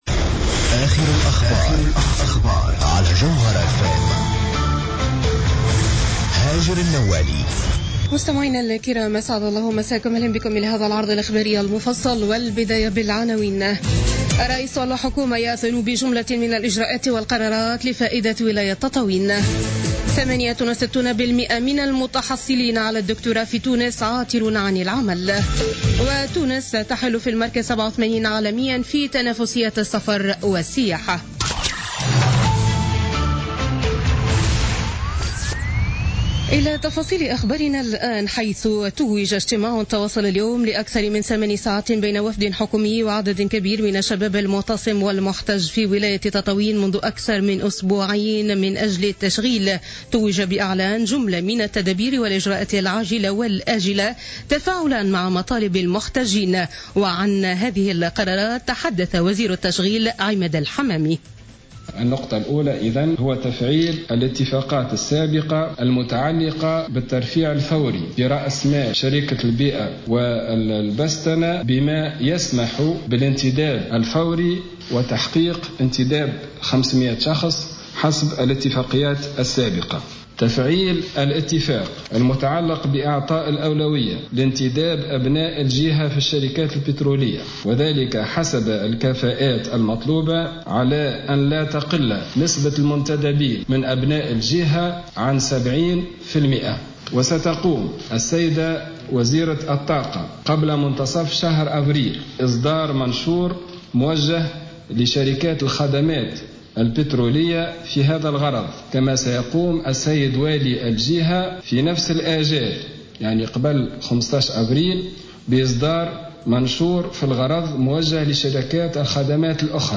نشرة أخبار السابعة مساء ليوم الاثنين 10 أفريل 2017